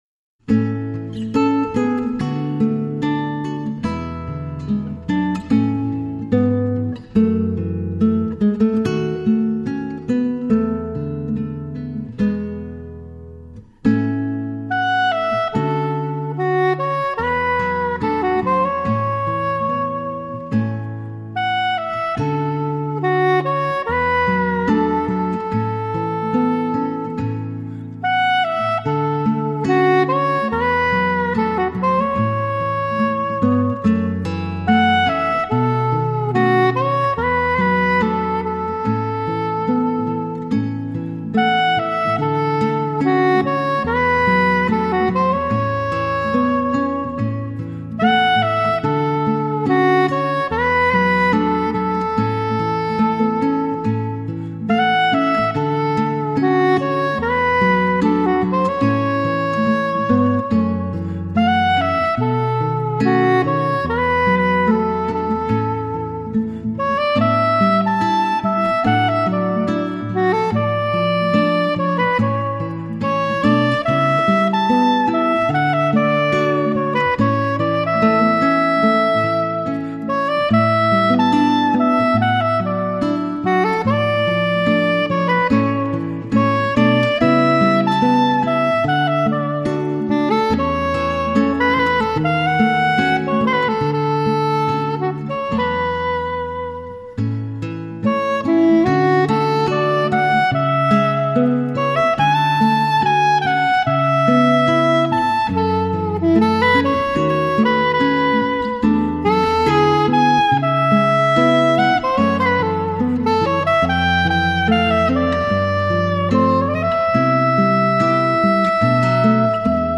Genre: Jazz / Funk / Fusion
Acoustic Guitar(10)